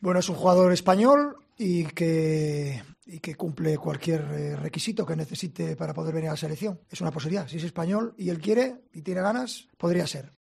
El seleccionador ha comparecido en rueda de prensa tras dar la lista de convocados para los amistosos ante Albania e Islandia, donde ha asegurado que Busquets no está por descanso.